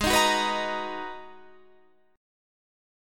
Abm9 chord